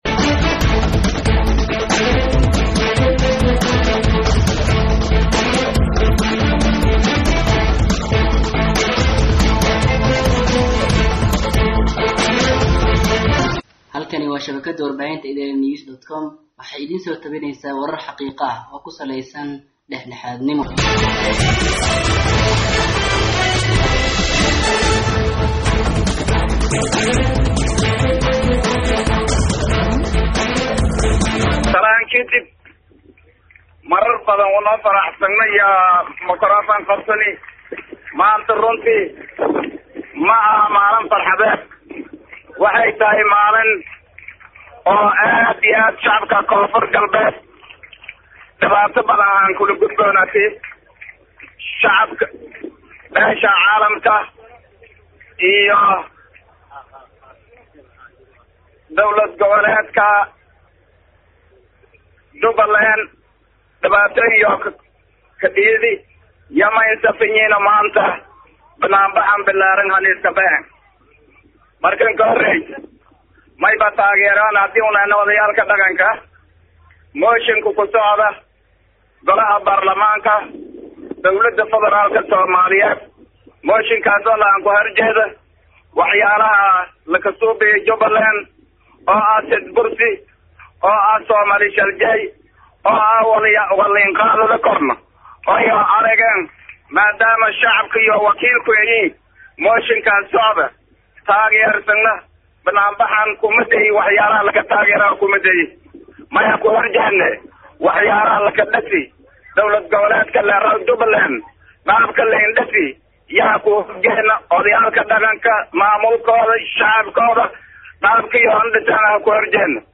Baydhabo(INO)-Banaanbax balaaran oo looga soo horjeedo faragalinta beesha caalamka gaar ahaan wakiilka gaarka ah ee Somalia Nicholas Kay, Urur goboleedka IGAD iyo dowladaha deriska ee Itoobiya iyo Kenya  ee dhismaha maamulada dalka Soomaaliya ayaa waxaa ka hadley masuuliyiin isugu jirta madax ka socota maamulka KG Somalia, Ururada bulshada gobolada, siyaasiyiin iyo duubabka dhaqanka.